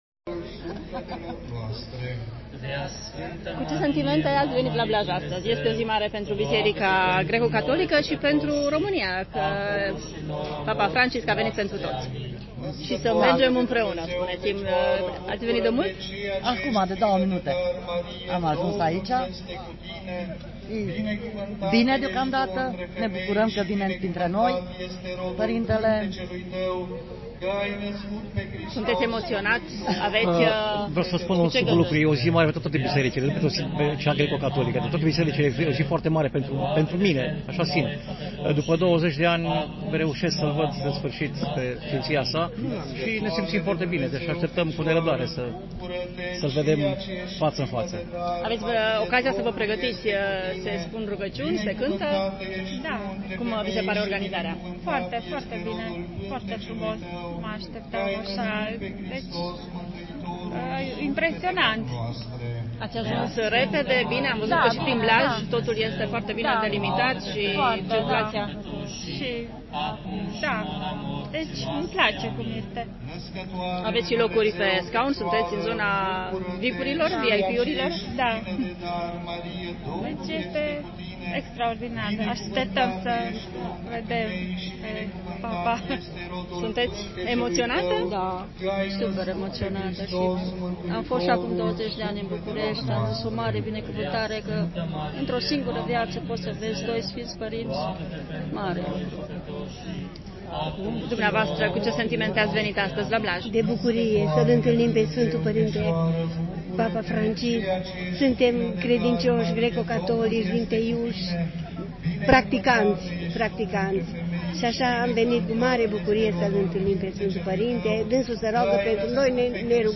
Vox-Blaj-1.mp3